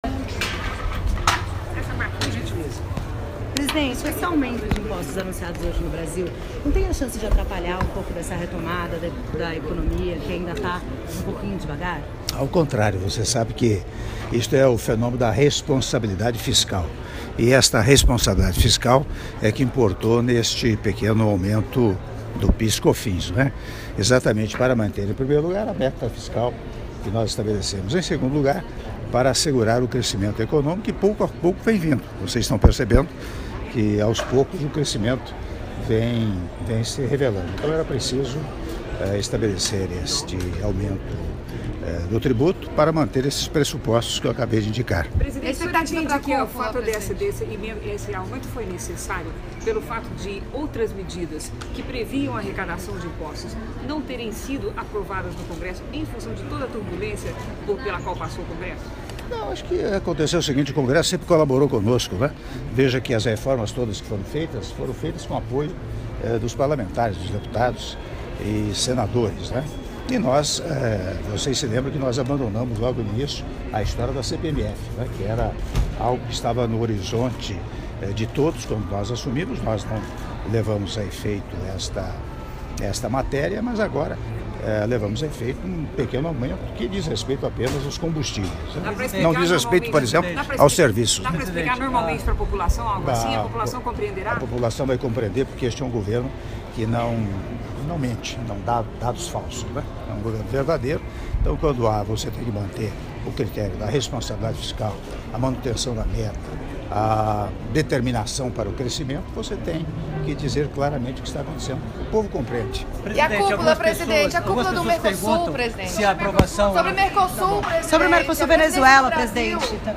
Áudio da entrevista coletiva concedida pelo Presidente da República, Michel Temer, na chegada ao hotel na Argentina - Mendoza/Argentina (03min12s)